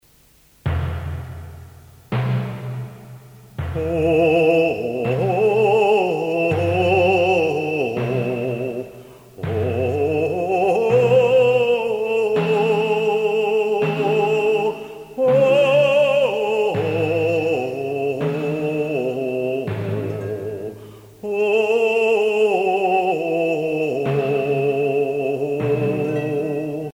musique traditionnelle russe
Genre strophique
Pièce musicale éditée